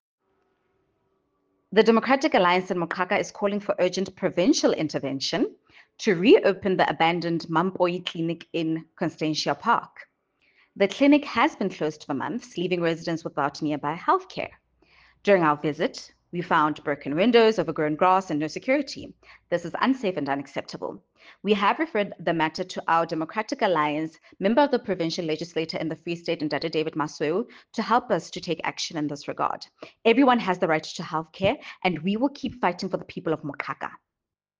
English and Sesotho soundbites by Cllr Mbali Mnaba and